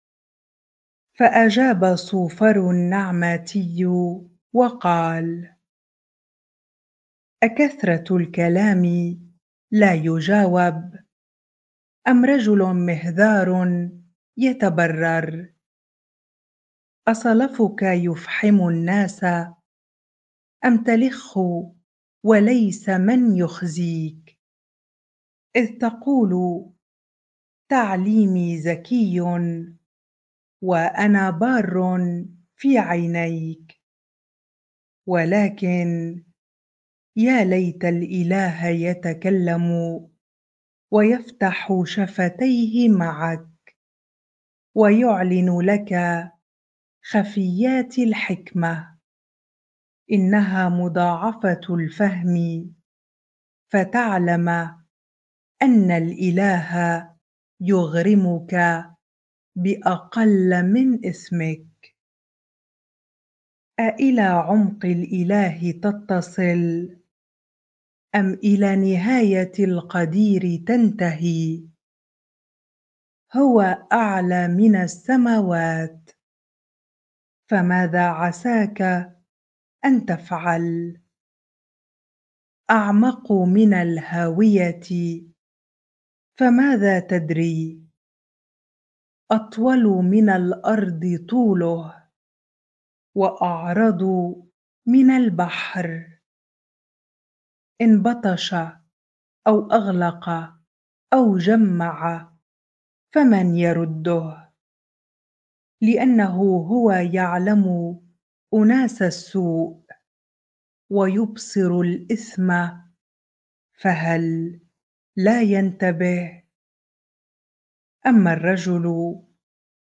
bible-reading-Job 11 ar